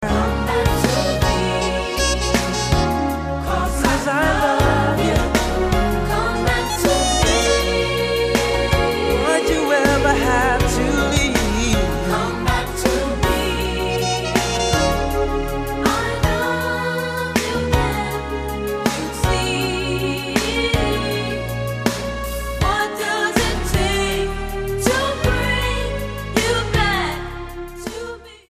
STYLE: Classical
soprano